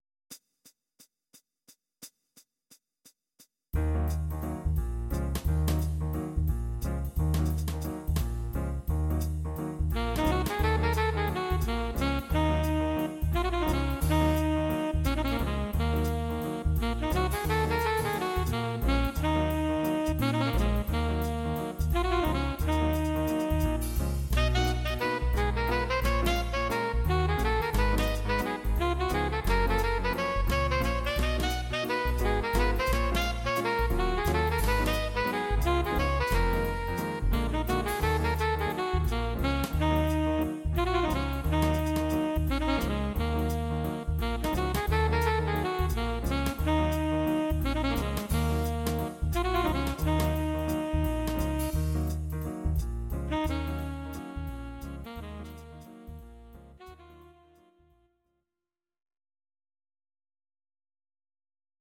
Ebm
Audio Recordings based on Midi-files
Jazz/Big Band, Instrumental, 1950s